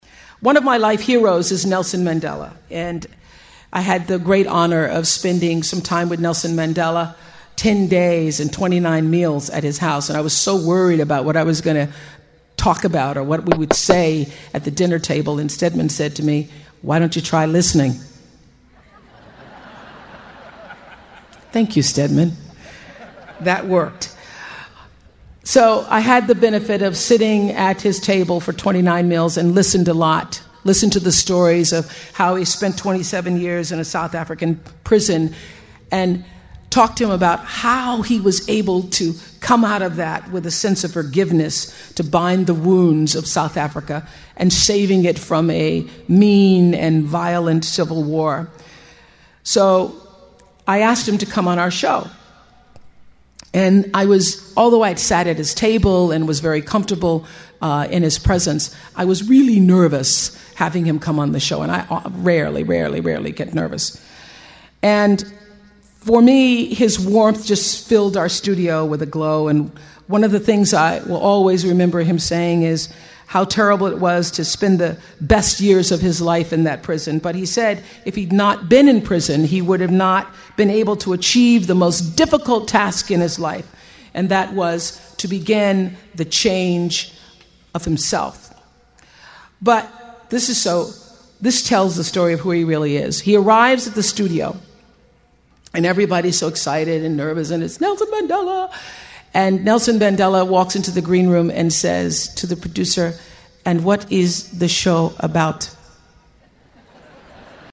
名校励志英语演讲 80:追随自己的心声,你们一定会成功 听力文件下载—在线英语听力室